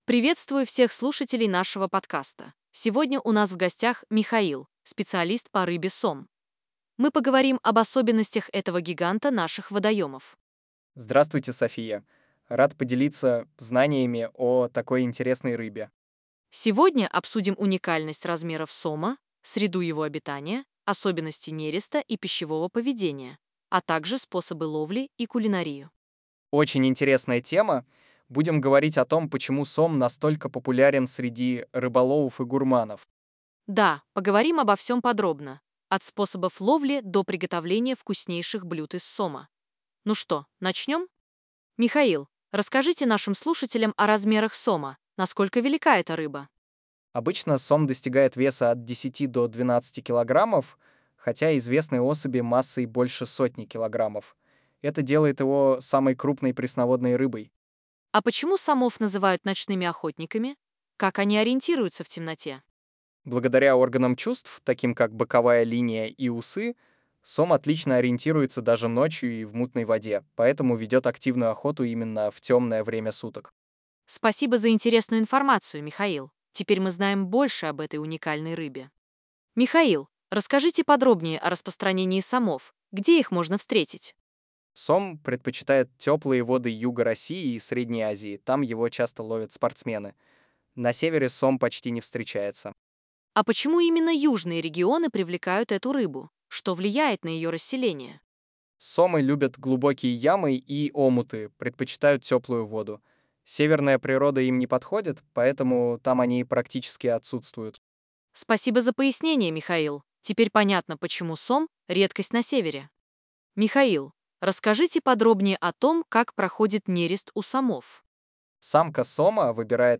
Что знает о сомах нейросеть:🙃
Только слух режет не правильное ударение :lol: